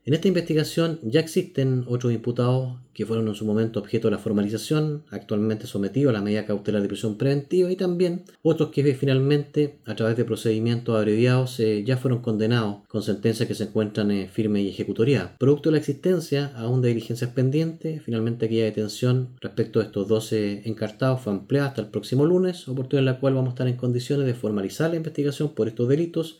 El fiscal Ramos añadió que otros integrantes de la RML ya fueron formalizados y condenados por esta causa y este lunes 2 de febrero, el Ministerio Público solicitará la prisión preventiva para ambos.